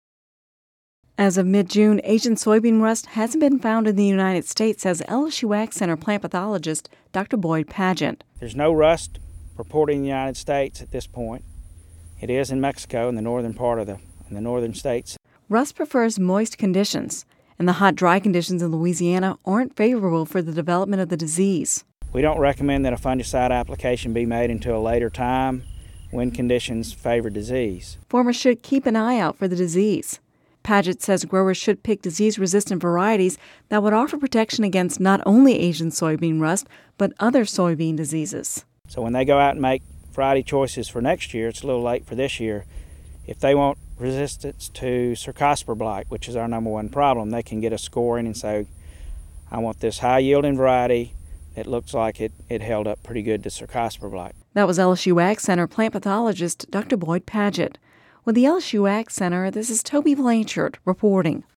(Radio News 07/05/10) As of mid-June